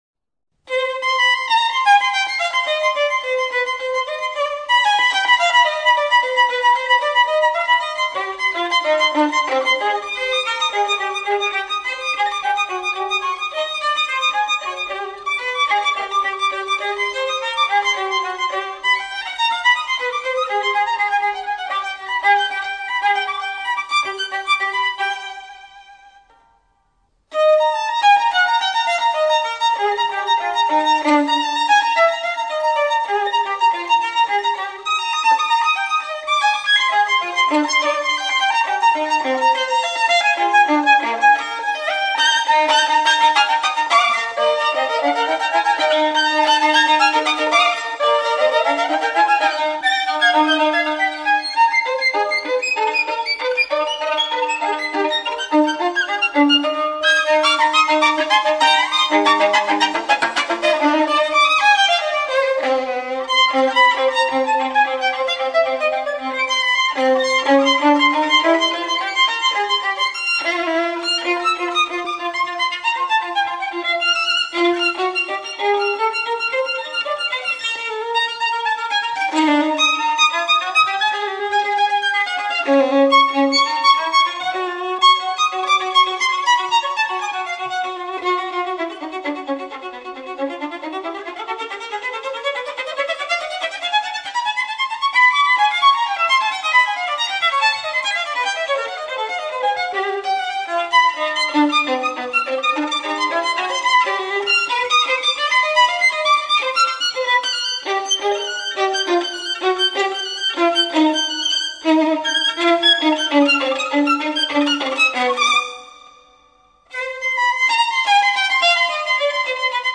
1976年歐洲錄音,原裝進口版